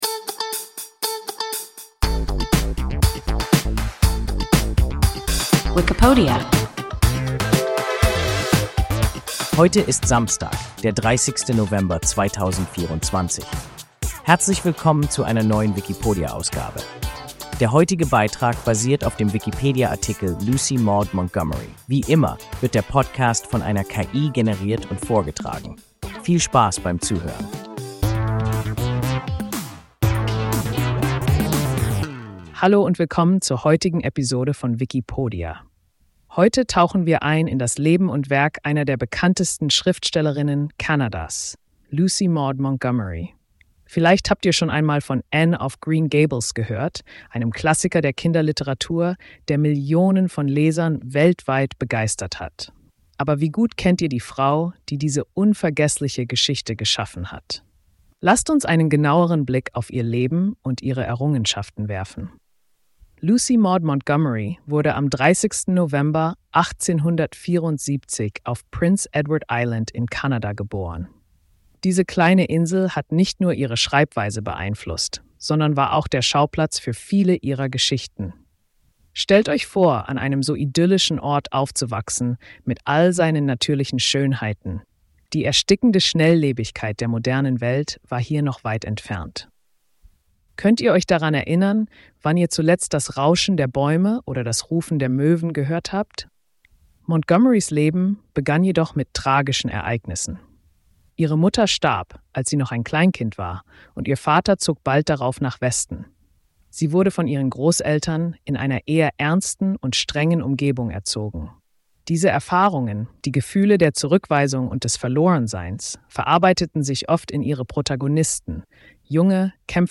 Lucy Maud Montgomery – WIKIPODIA – ein KI Podcast